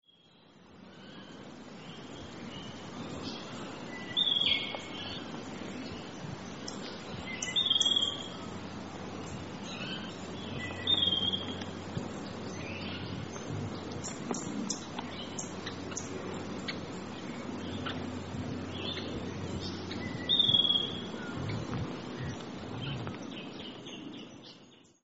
Brown Thornbill - Acanthiza pusilla
Voice: rich 'pee-orr', other small squeaks and churrs.
Call 1: 'pee-orr' call
Brown_Thornb_peeorr.mp3